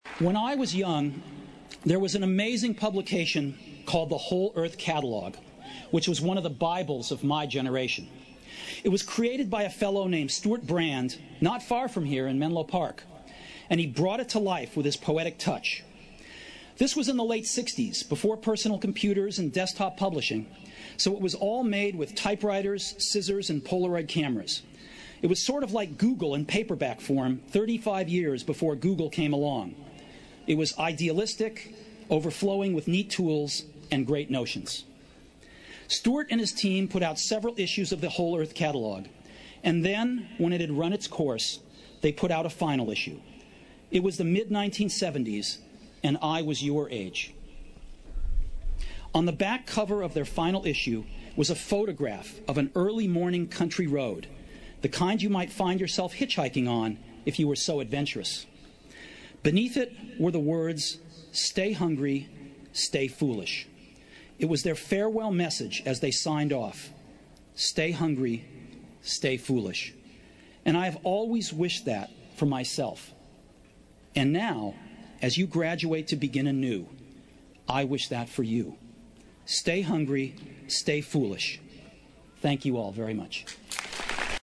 财富精英励志演讲11：我生命中的三个故事(11) 听力文件下载—在线英语听力室